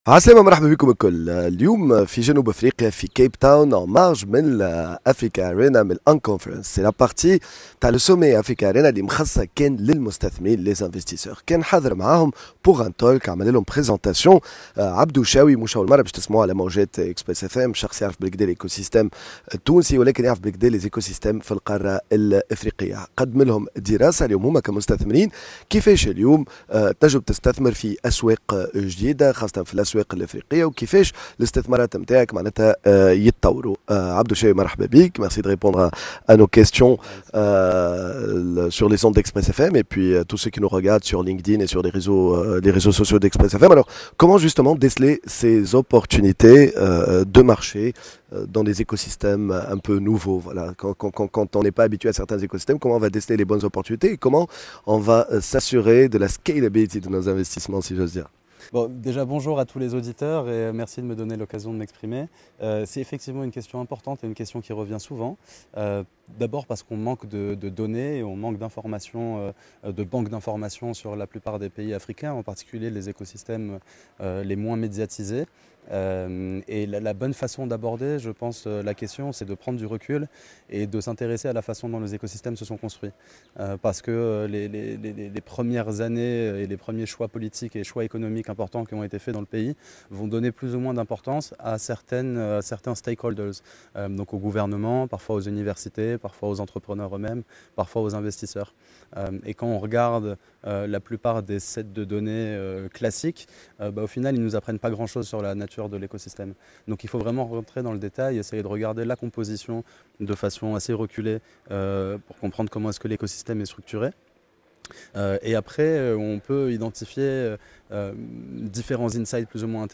en direct de l’un des plus importants événements d’investisseurs en startups Africarena à Capetown